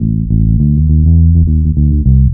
TR BASS 2.wav